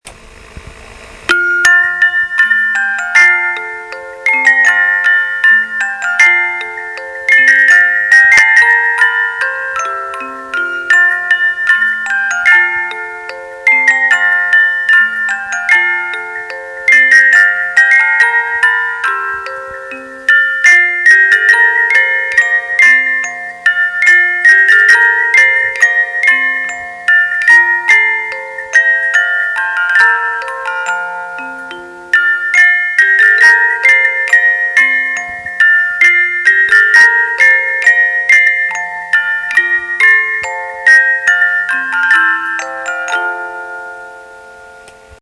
Technische Details zu Walzen-Spieldosen
mp3 Datei - Tonqualit�t wg. Komprimierung vermindert